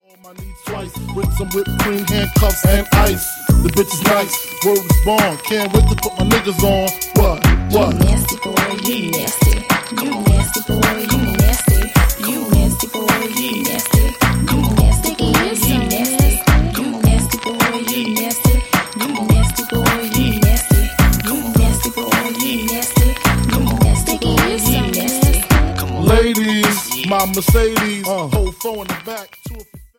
Hip Hop 90s Music